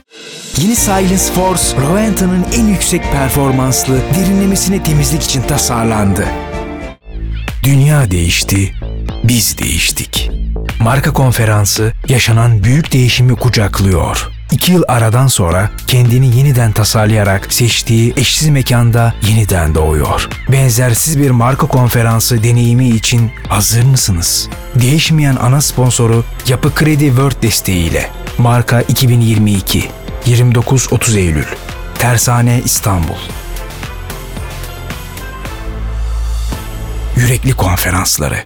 Deep, Cool, Warm
Commercial